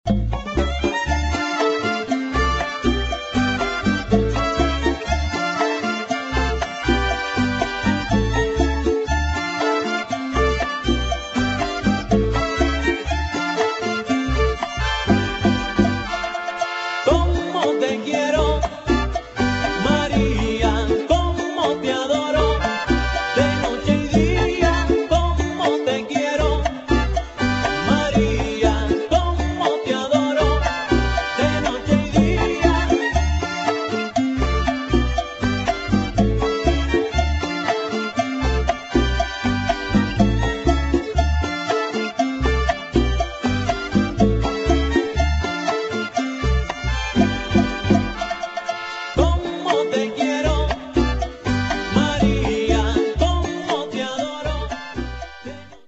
[ LATIN JAZZ / DEEP HOUSE ]